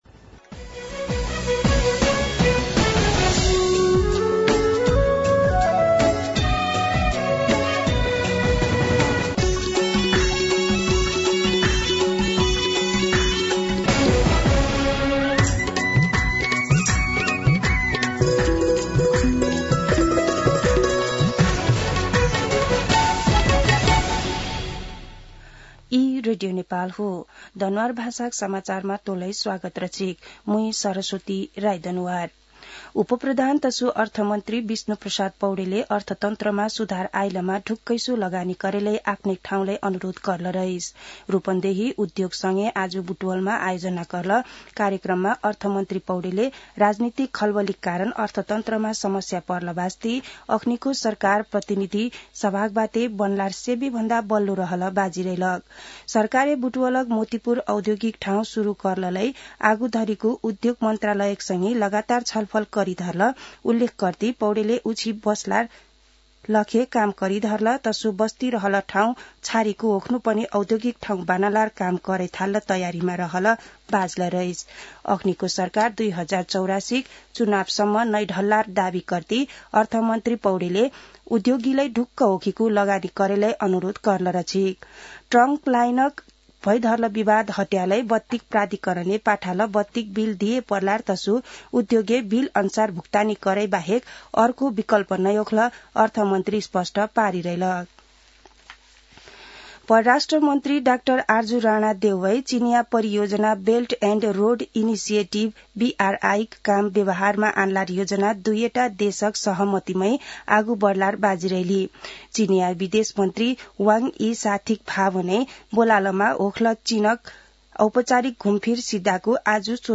दनुवार भाषामा समाचार : १६ मंसिर , २०८१
Danuwar-News-15.mp3